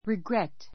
riɡrét リ グ レ ト